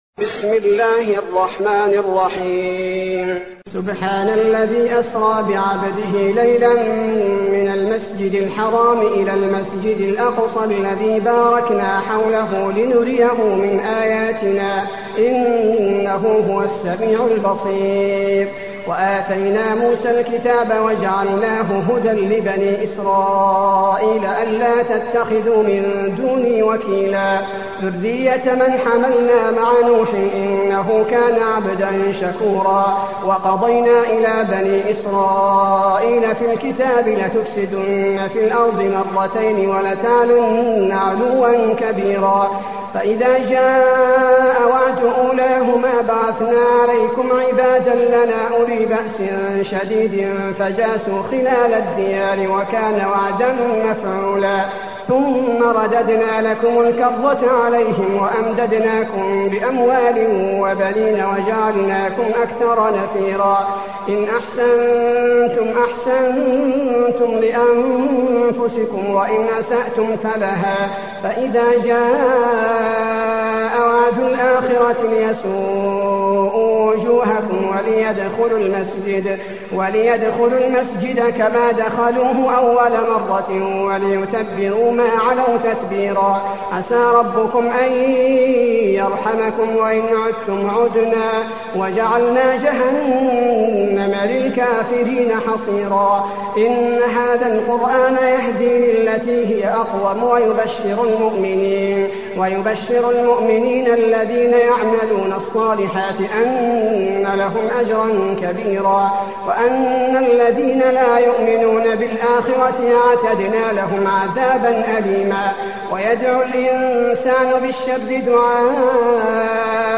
المكان: المسجد النبوي الإسراء